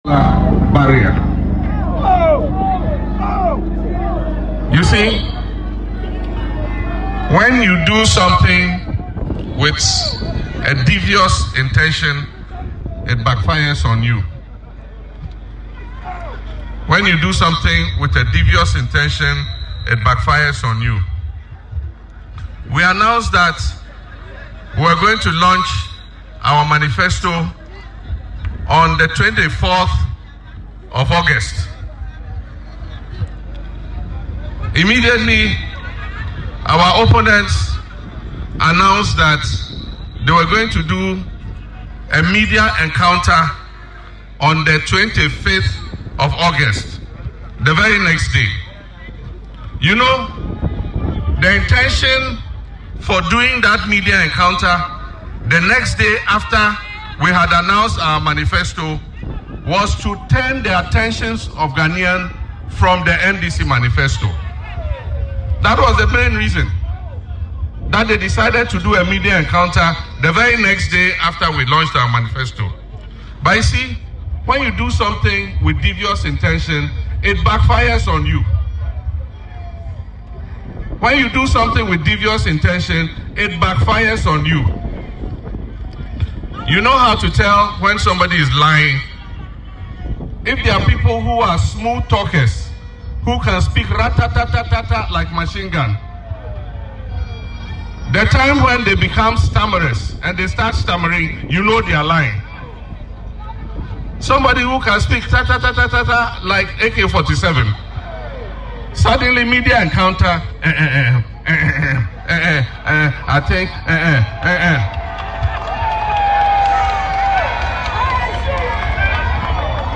During his tour of the Greater Accra Region, Mr Mahama accused the NPP of harbouring devious intentions, adding that their media engagement was an attempt to divert attention from the NDC’s manifesto launch.